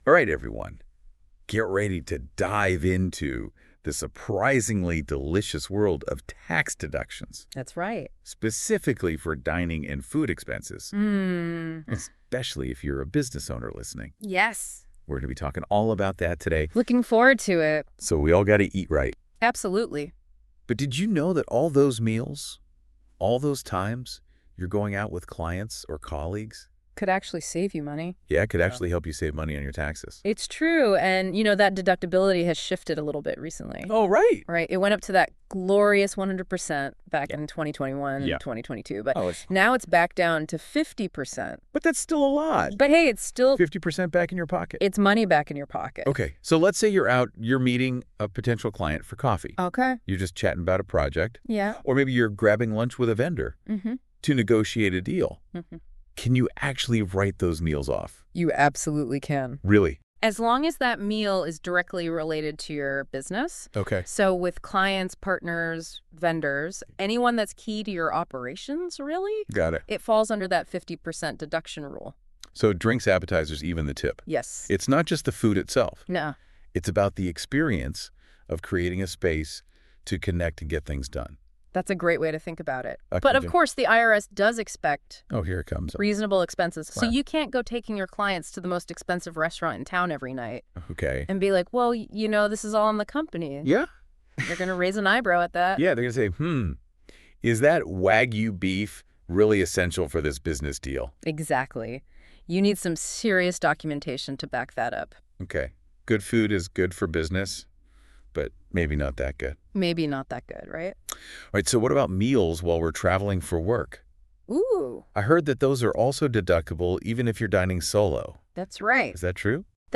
Listen to Prosper Tax Tips - Save Thousands with Dining and Food Tax deductions by Prosper Tax & Financial (Voice by A.I.) on Podcast Addict.